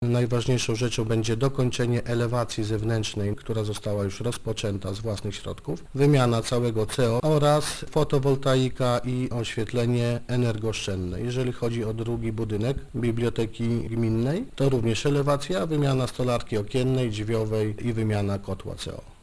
Zakres robót będzie podobny, ale w przypadku budynku szkolnego wójt gminy Stary Brus Paweł Kołtun podkreśla, że: